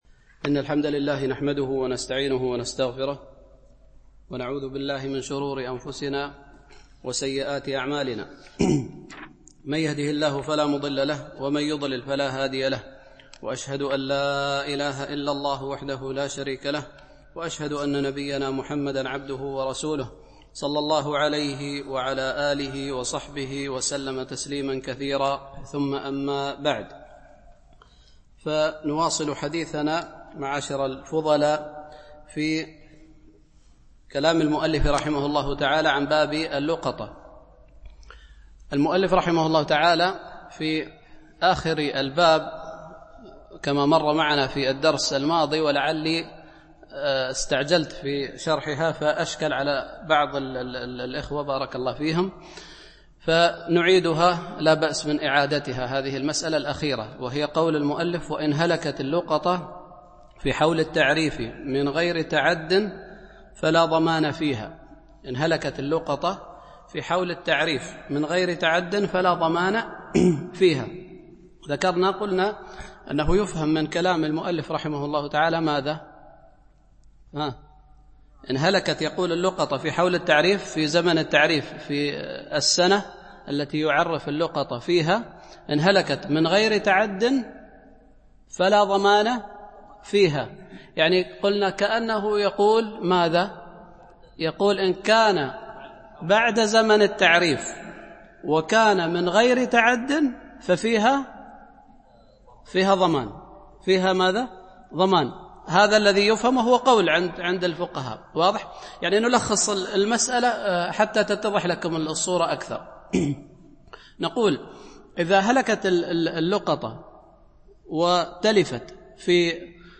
شرح عمدة الفقه ـ الدرس 78 (فصل اللقيط وباب السبق)
Mono